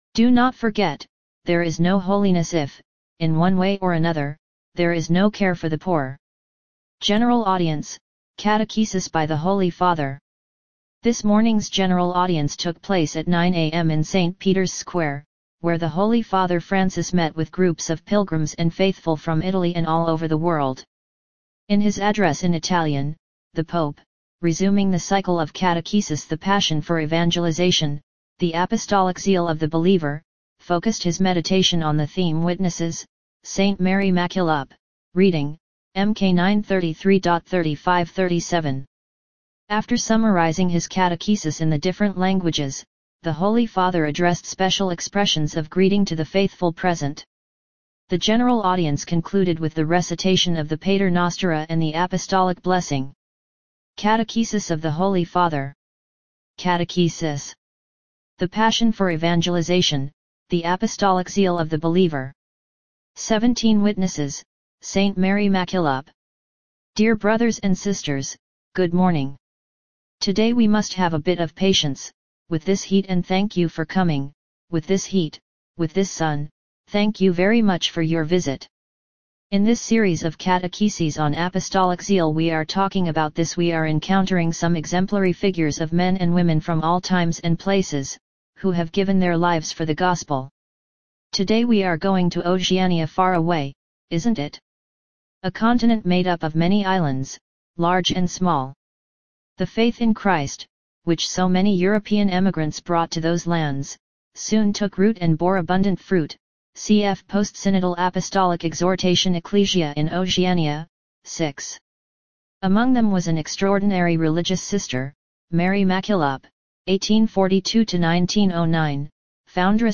General Audience: Catechesis by the Holy Father
This morning’s General Audience took place at 9:00 a.m. in St. Peter’s Square, where the Holy Father Francis met with groups of pilgrims and faithful from Italy and all over the world.